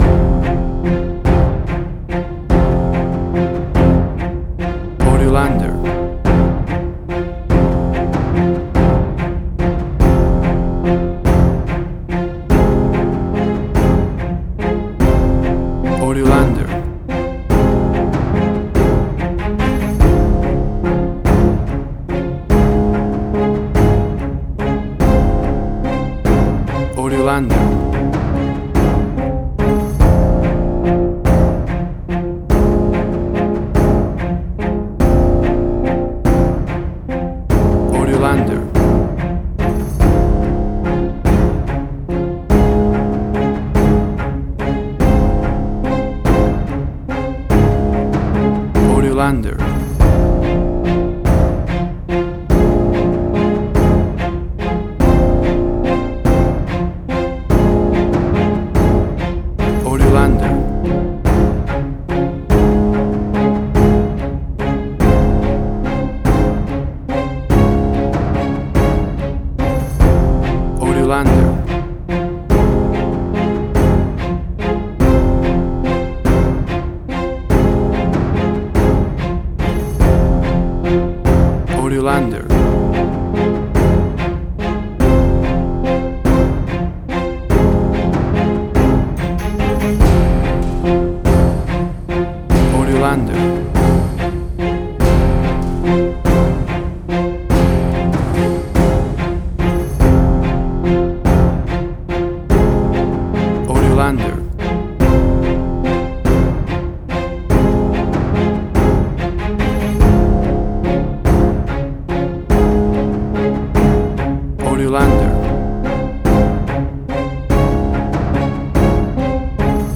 Suspense, Drama, Quirky, Emotional.
Tempo (BPM): 144